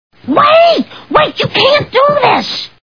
The Simpsons [Marge] Cartoon TV Show Sound Bites